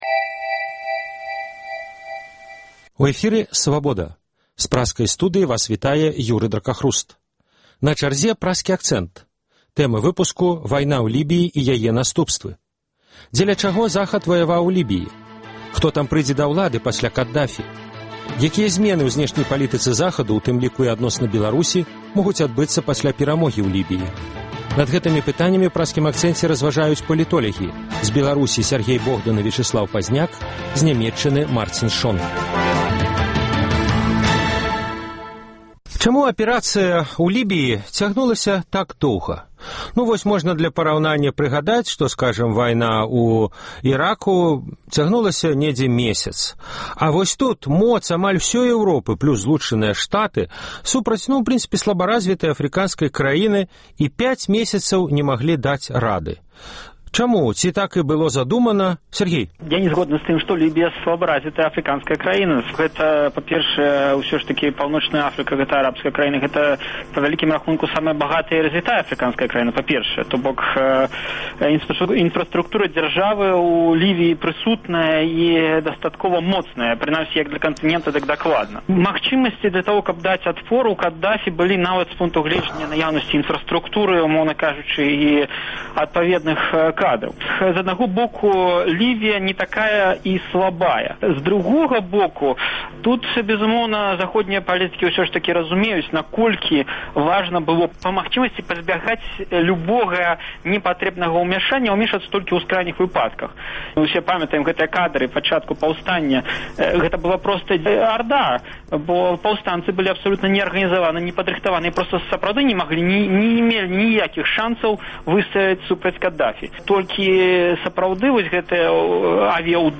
Якія зьмены ў зьнешняй палітыцы Захаду, у тым ліку і адносна Беларусі, могуць адбыцца пасьля перамогі ў Лібіі? Удзельнічаюць палітолягі